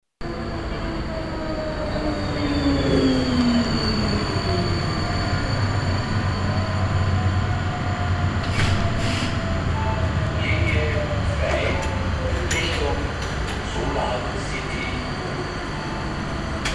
LISA – Liniensprachansage:
Man erfährt über Außenlautsprecher am Fahrzeug die Liniennummer und das Endziel.
Hörbeispiel Straßenbahnlinie 2: